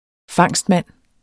Udtale [ ˈfɑŋˀsdˌmanˀ ]